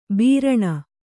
♪ bīraṇa